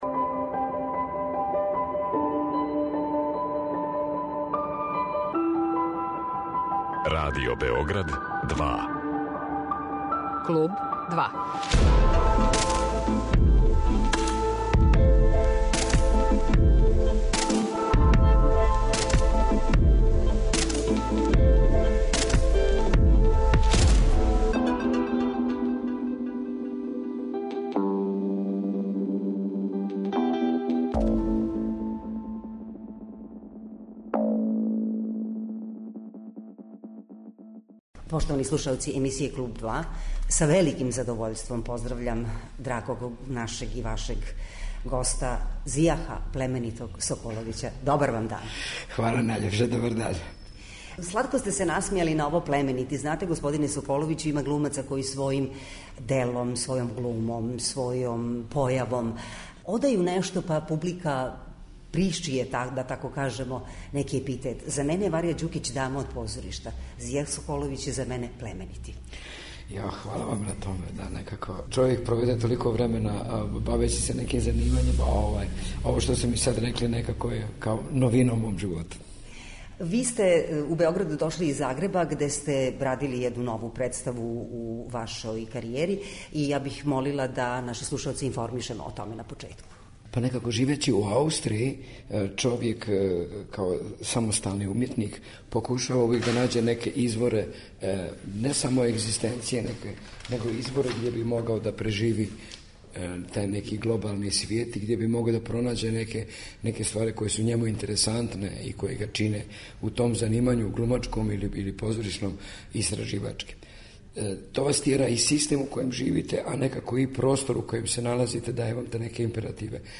Гост емисије 'Клуб 2' је познати драмски уметник Зијах Соколовић.